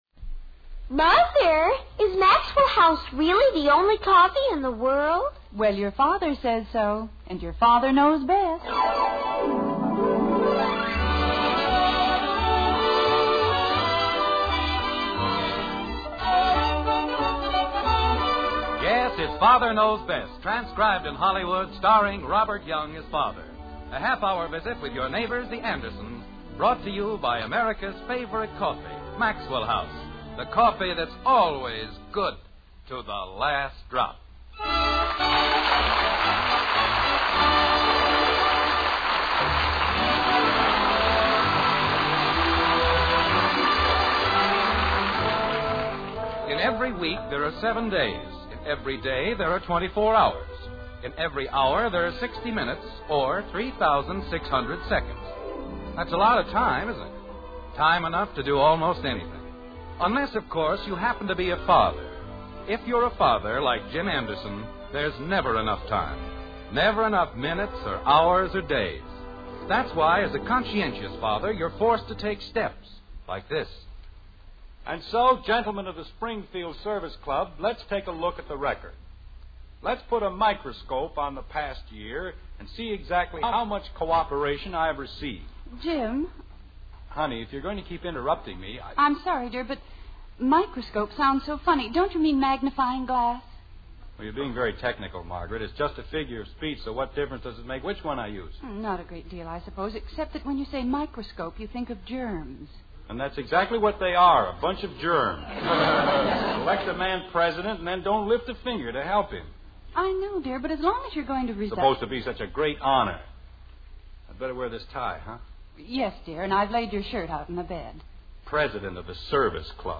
The Father Knows Best Radio Program